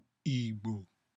Pronunciation[ásʊ̀sʊ̀ ìɡ͡bò]